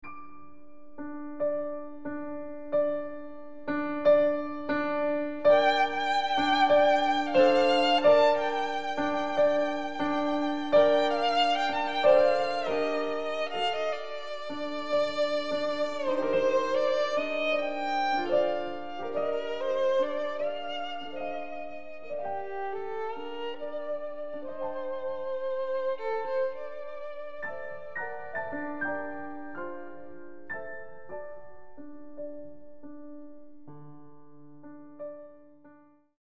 Super Audio CD